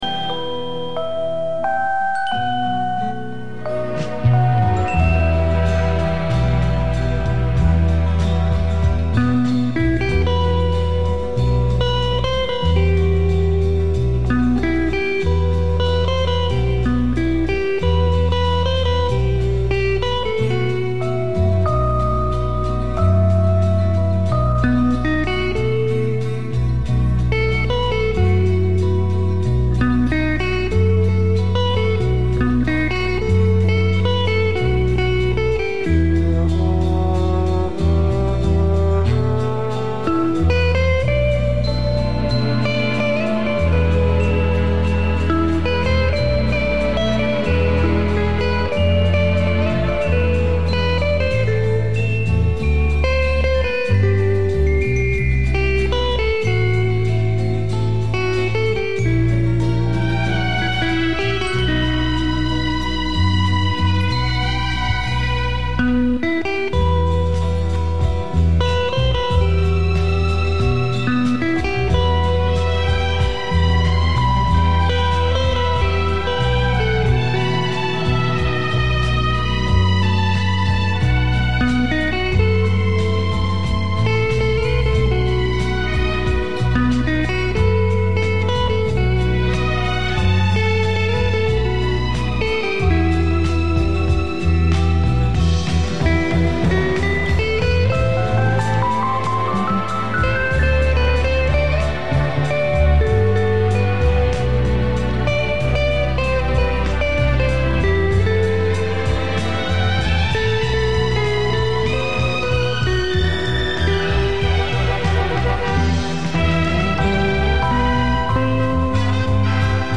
Очень прошу, угадайте пожалуйста несколько инструментальных мелодий.